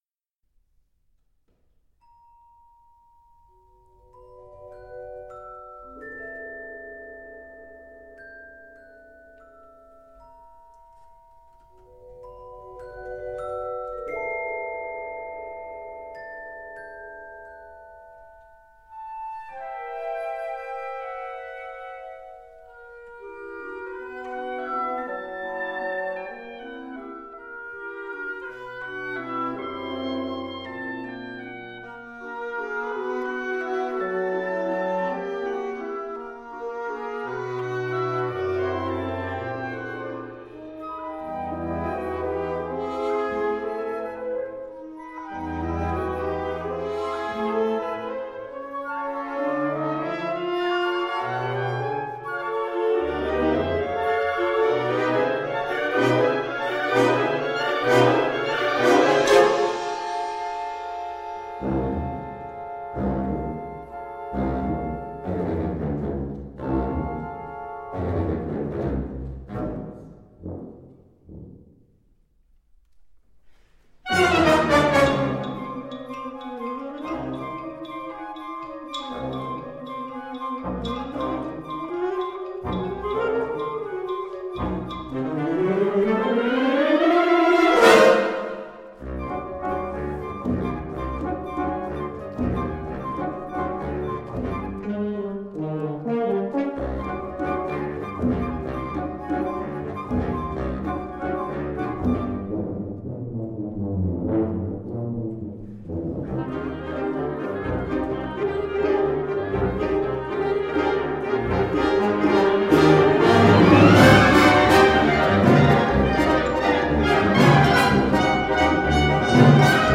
Catégorie Harmonie/Fanfare/Brass-band
Sous-catégorie Musique à vent contemporaine (1945-présent)
Instrumentation Ha (orchestre d'harmonie)